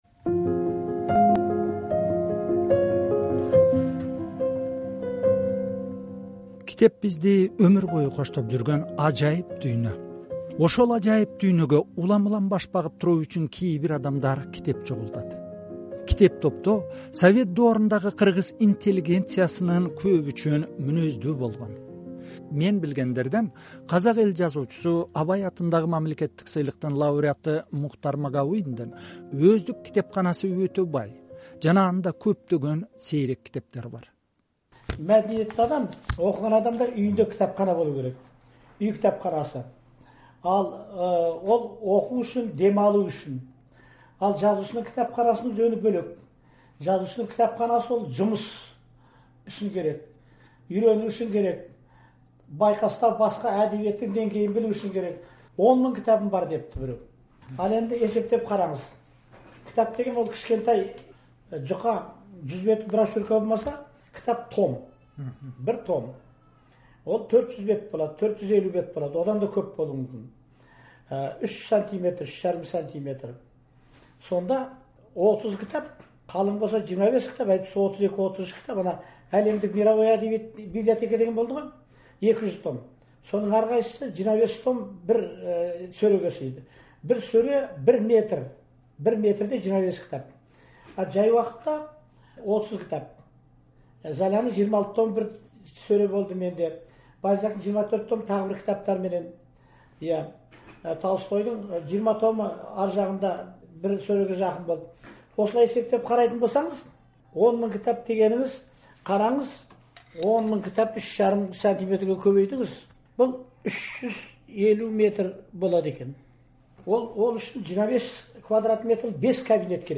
Казак эл жазуучусу Мухтар Магауин менен китептин көөнөргүс орду тууралуу маек.